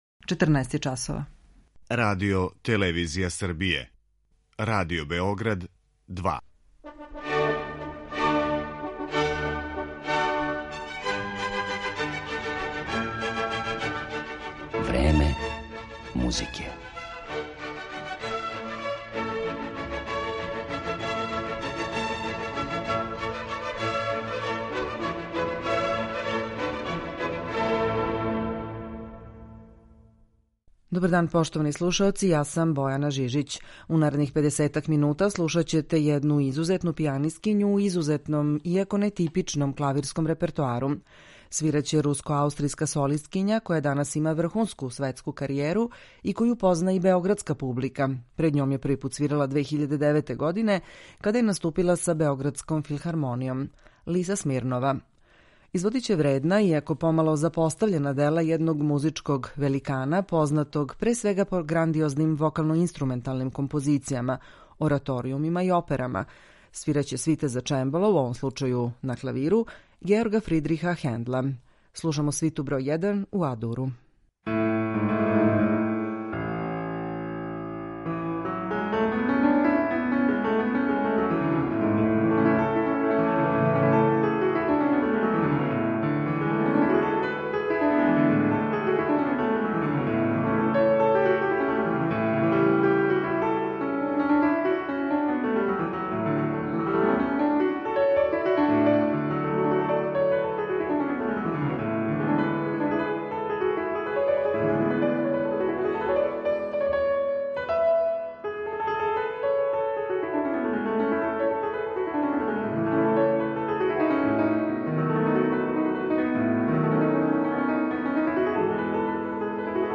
Svita za čembalo Georga Fridirha Hendla
pijanistkinja
Ovu neobičnu i talentovanu solistkinju danas ćemo predstaviti u jednom od najboljih i najuzbudljivijih klavirskih izvođenja svita za čembalo Georga Fridirha Hendla.